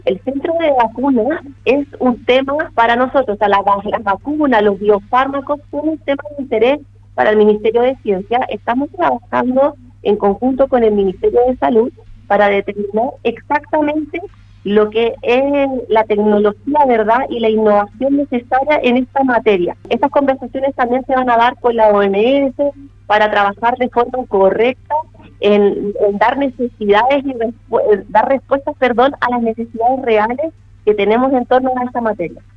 En conversación con Radio Sago, la ministra de Ciencias, Tecnología e Innovación, Silvia Díaz, abordó la posibilidad de contar con la infraestructura necesaria para elaborar vacunas en Chile, ante lo cual expuso que existen conversaciones al respecto.